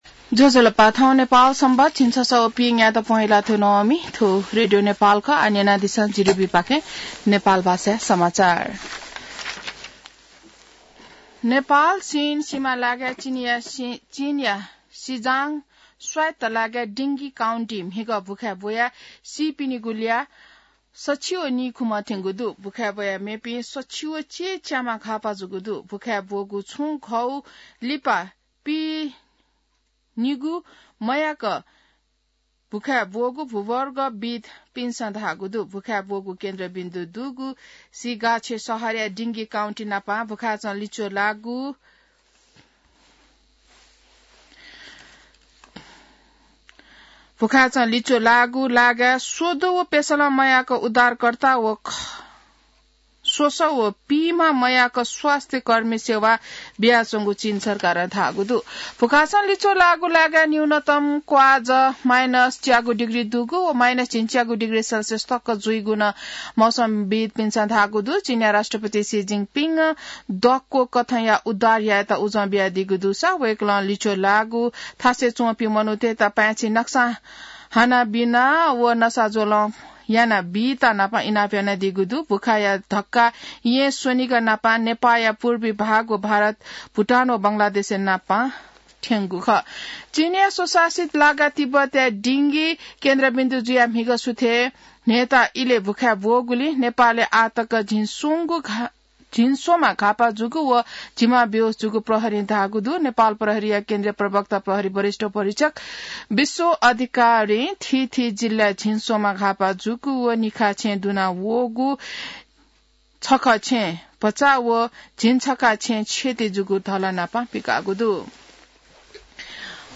नेपाल भाषामा समाचार : २५ पुष , २०८१